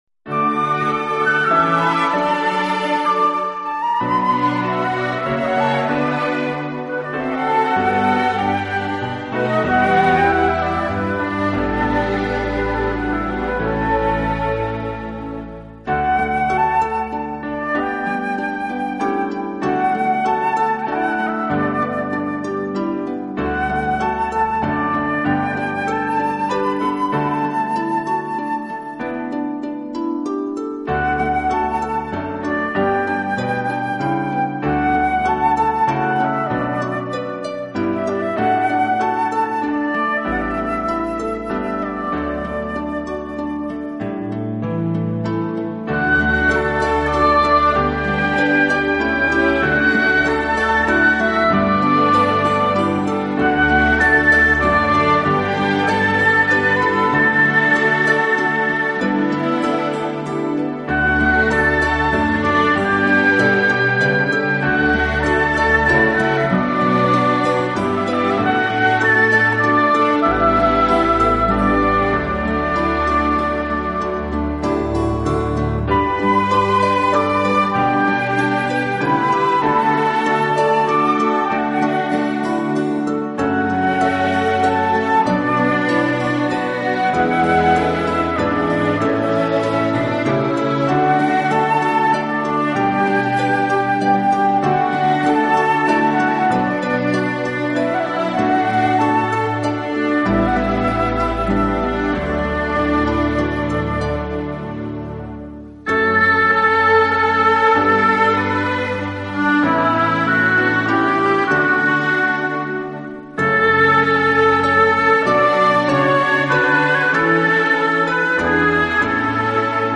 的音乐总是给人那幺大气、庄严和堂皇的感觉。